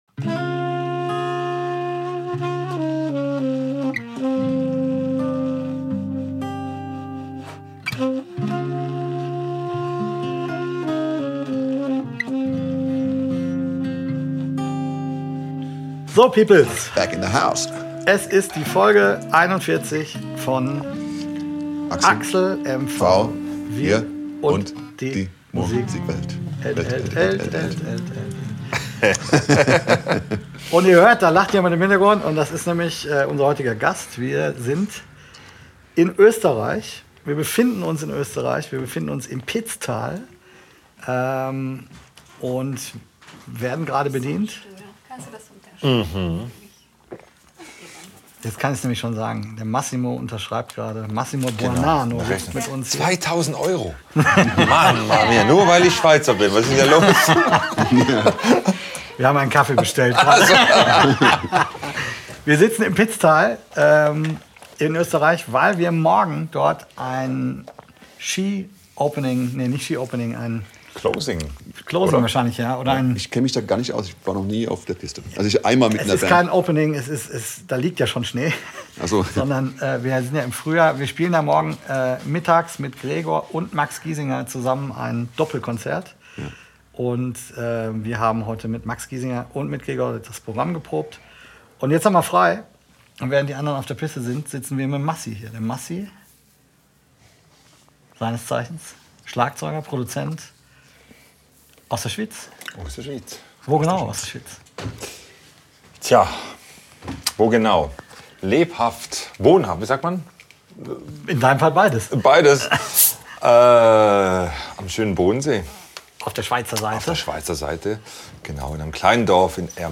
in einem alpenländischen Hotel im Pitztal